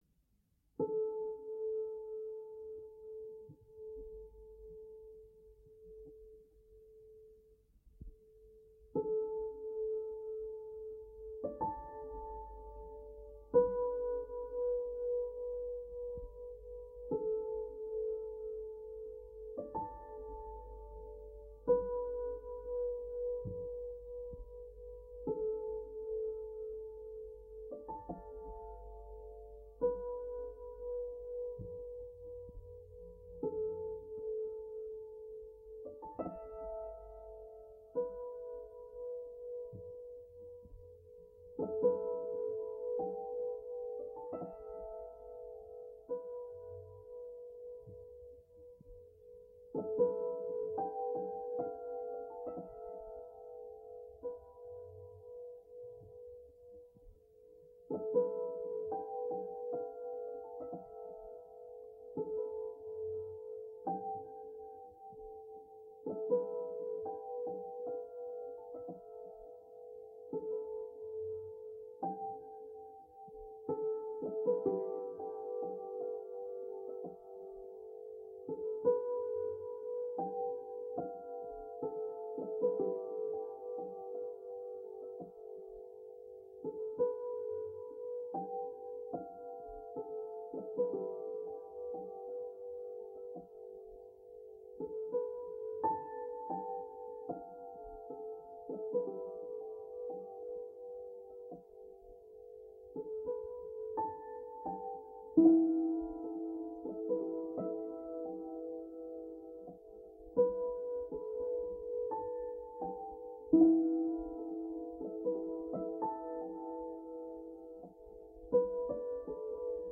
I play on the piano short random-generated diatonic groups of notes (from 1 to 6 notes each). Each group is played one time only. A 6 seconds echo repeat and mix the notes building melodies, form, rhythm and eventually emotions.
for piano and delay Back